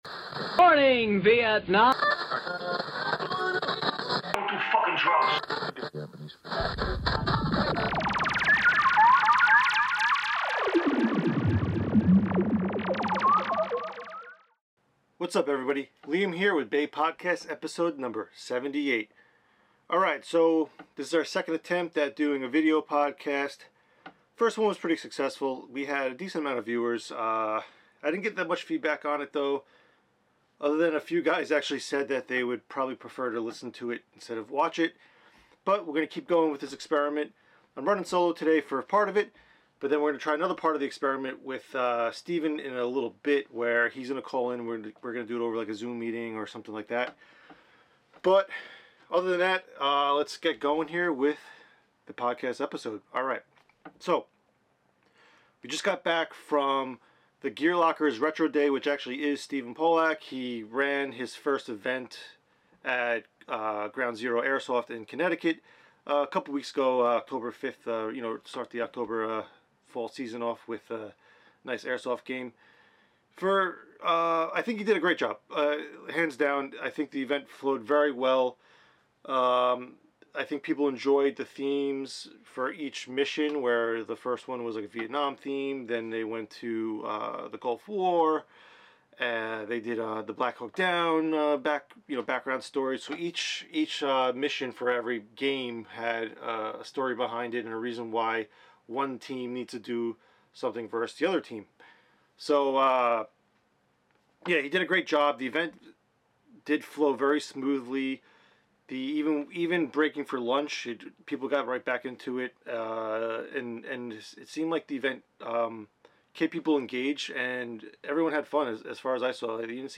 Listen to The BAE Team discuss their events and other subjects in the airsoft community.